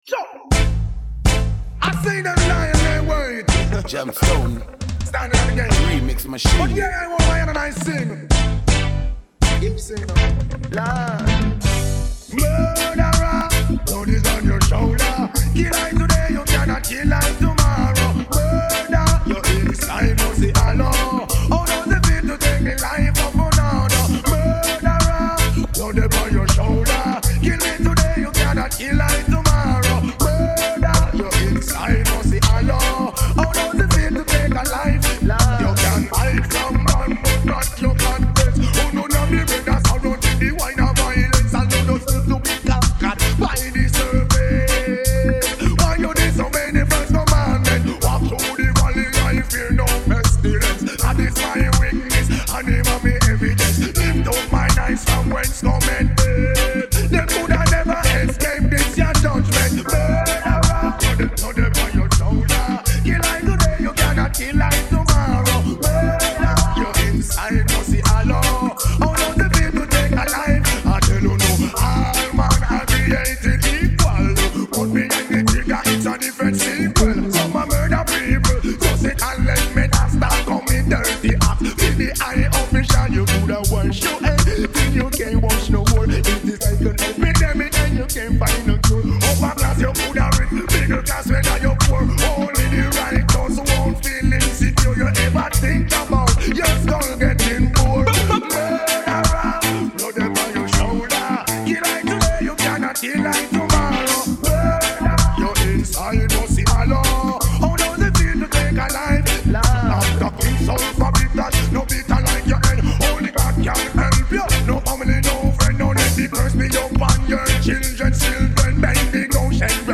Vocal track